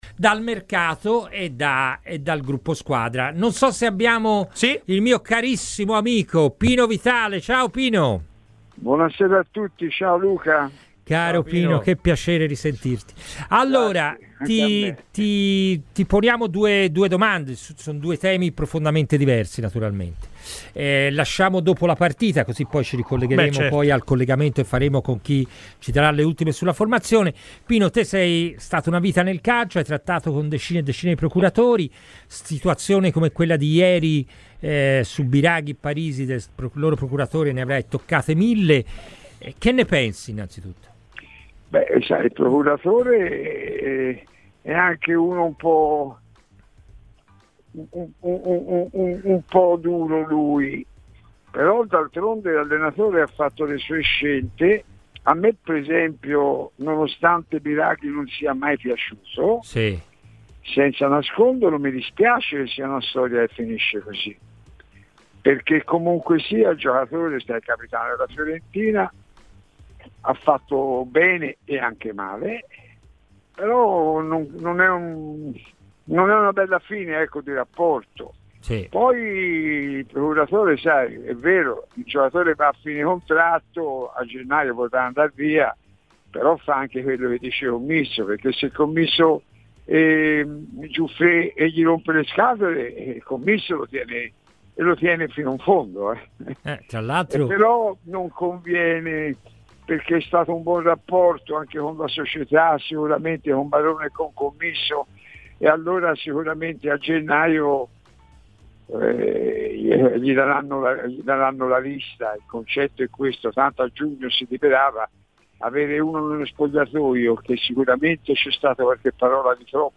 ai microfoni di Radio FirenzeViola durante la trasmissione "Palla al Centro"